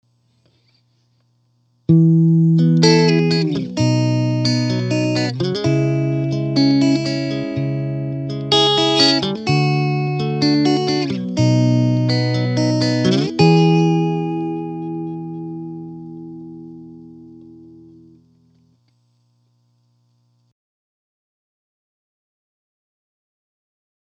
Here are some clips that I put together based upon the process above (I skipped recording head-on, off center):
Clean (Squier Classic Vibe Tele 50’s)
1. Mic head-on, dead-center